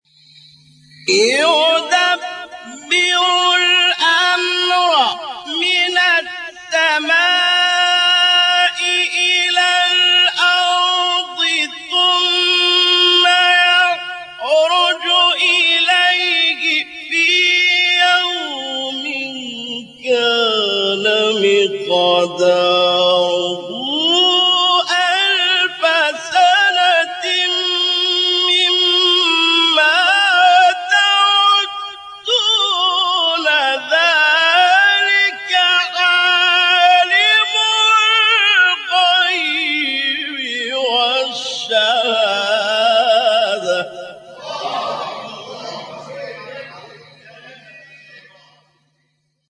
گروه فعالیت‌های قرآنی: مقاطعی صوتی از قاریان برجسته جهان اسلام که در مقام رست اجرا شده‌اند، ارائه می‌شود.
مقام رست